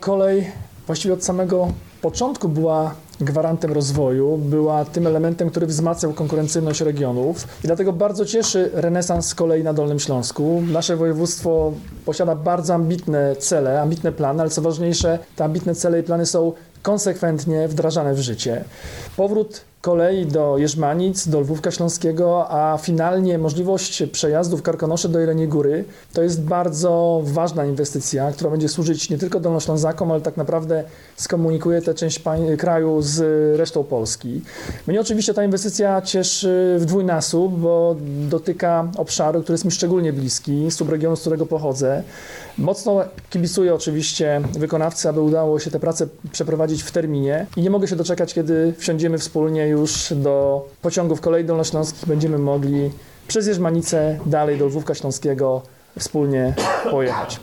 Fakt, że rozwój kolei wzmacnia konkurencyjność regionu podkreśla Jarosław Rabczenko, członek zarządu województwa.